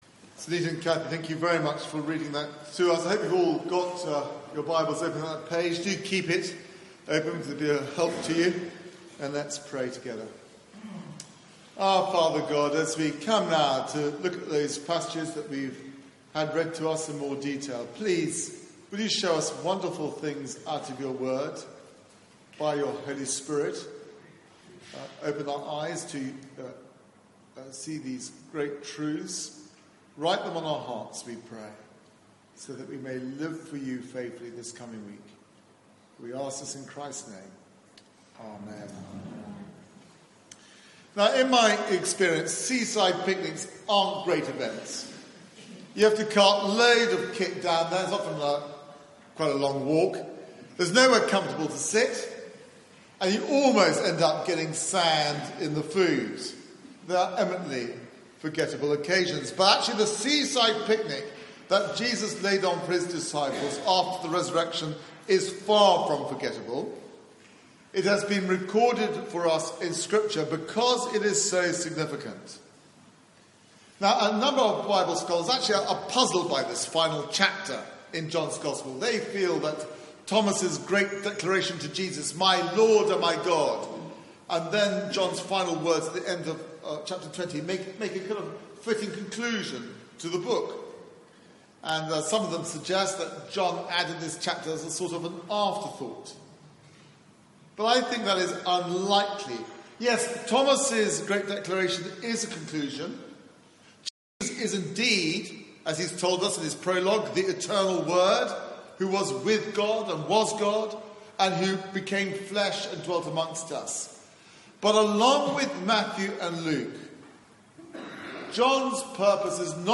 Media for 9:15am Service on Sun 15th Apr 2018 09:15 Speaker
Fishing Again Sermon